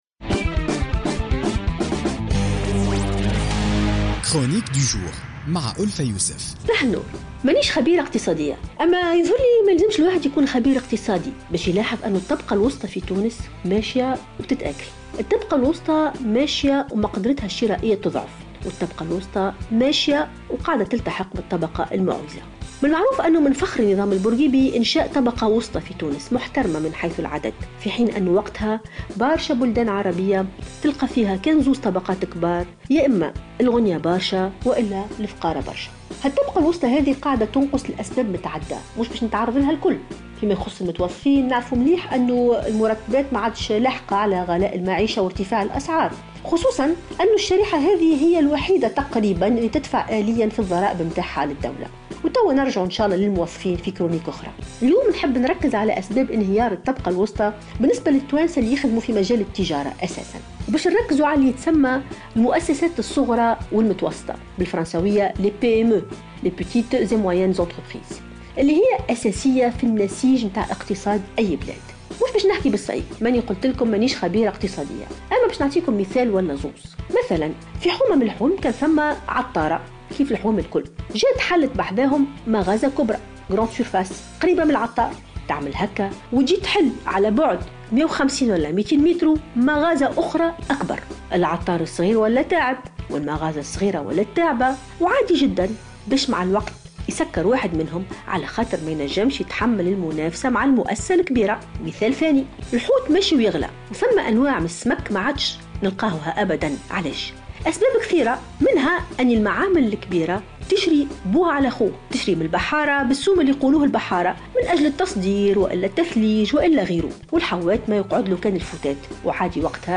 تحدثت الباحثة ألفة يوسف في افتتاحية اليوم الاثنين 14 نوفمبر 2016 عن الطبقة المتوسطة في تونس والتي بدأت تتآكل تدريجيا وتنهار وفق تعبيرها.